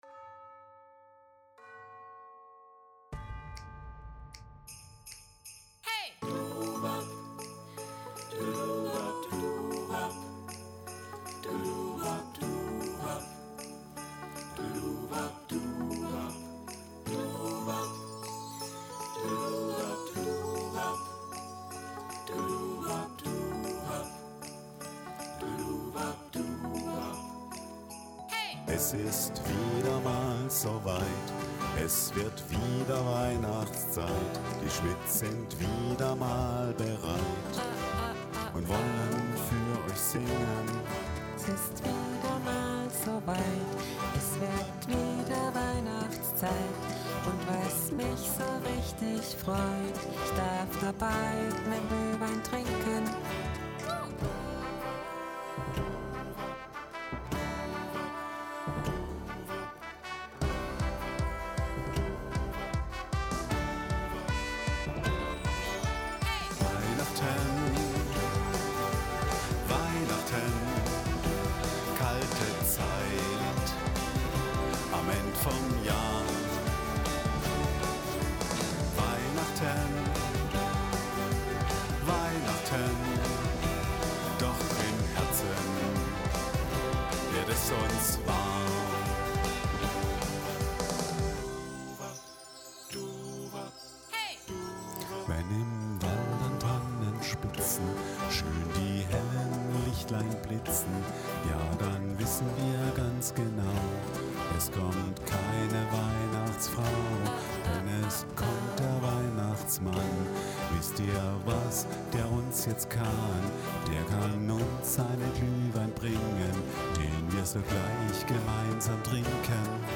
Auch wenn wir den/die/das Ein*innen auch nicht persönlich sehen werden, so hoffen wir doch, dass unser Gruß in 155bpm ein paar Minuten Freude bringt und auf das Weihnachsfest einstimmt.
Ohne Bässe auf dem Handy geht doch etwas der Flair verloren – und wir wollen ja die Weihnachtszeit dadurch nicht gefährden.
A f# D E // G e C D…
PPS: falsche Töne sind vermutlich Absicht 🙂